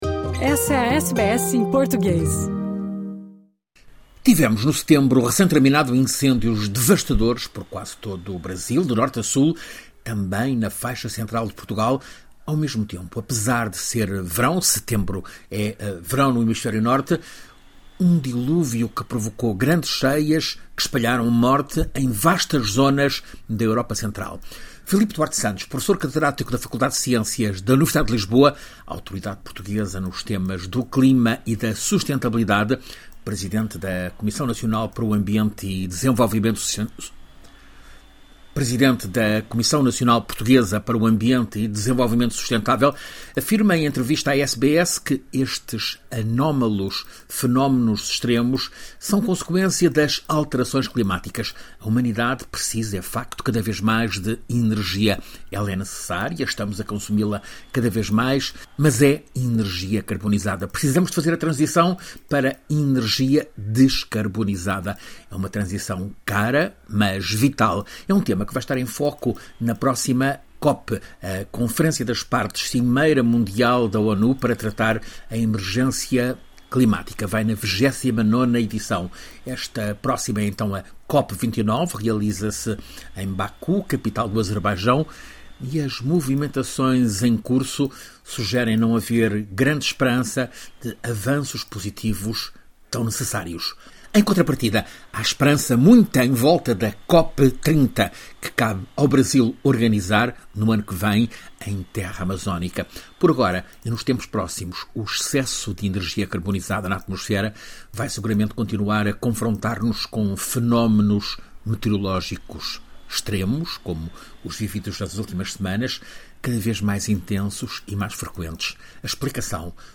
Filipe Duarte Santos, professor catedrático na Faculdade de Ciências da Universidade de Lisboa, autoridade portuguesa nos temas do clima e da sustentabilidade, presidente da Comissão Nacional para o Ambiente e Desenvolvimento Sustentável, afirma nesta entrevista à SBS que estes anômalos fenômenos extremos são consequência das alterações climáticas.